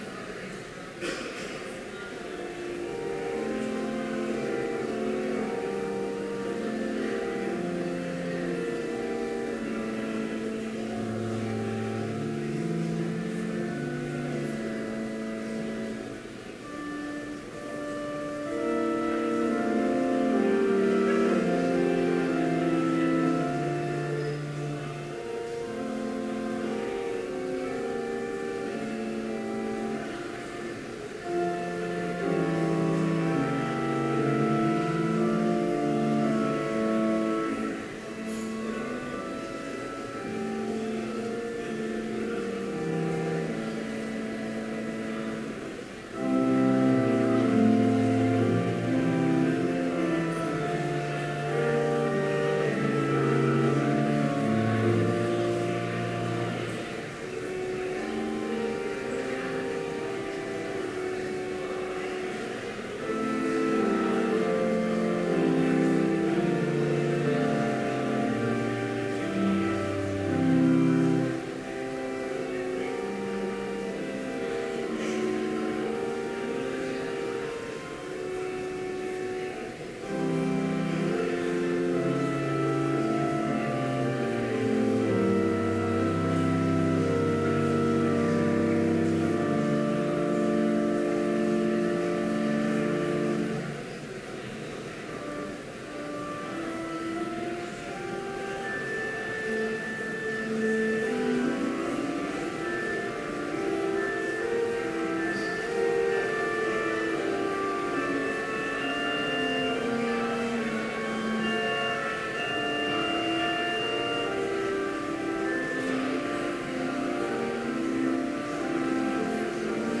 Complete service audio for Chapel - September 10, 2014